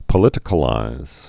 (pə-lĭtĭ-kə-līz)